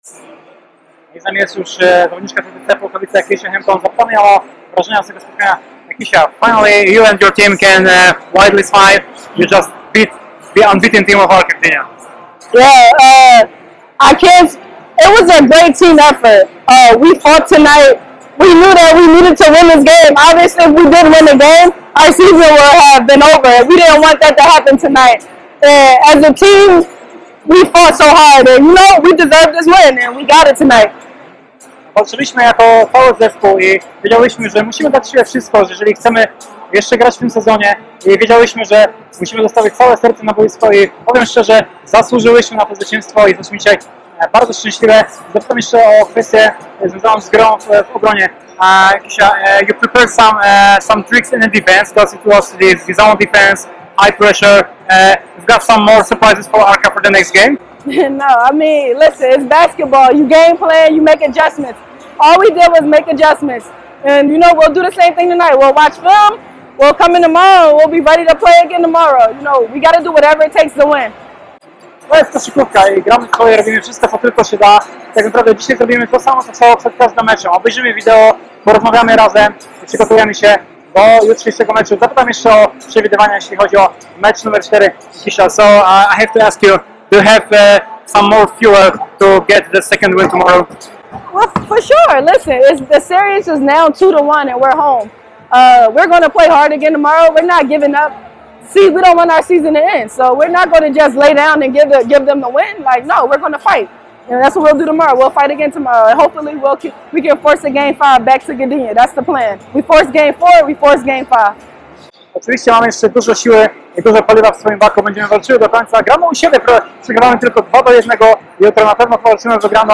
Pomeczowe wypowiedzi - audio